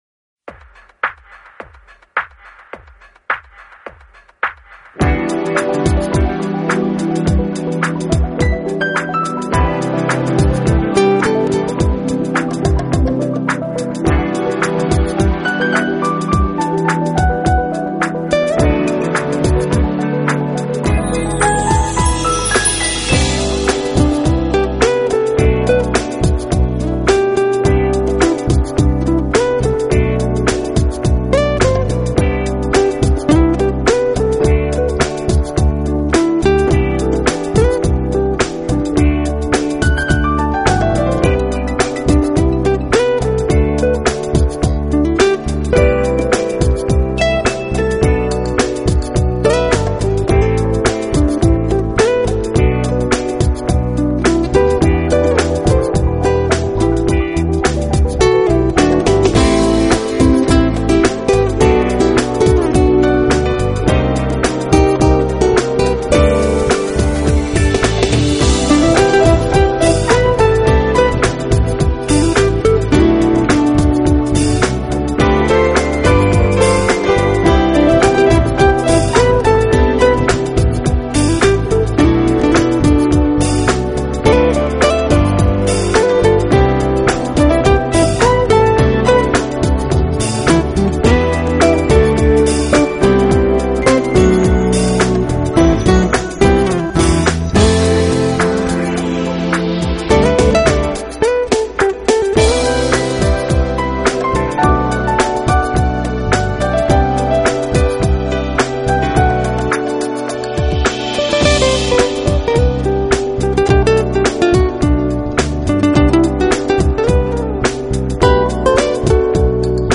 【爵士吉他】
听他曲子，你会发现每一首曲子里 的军鼓都是打的非常有力度。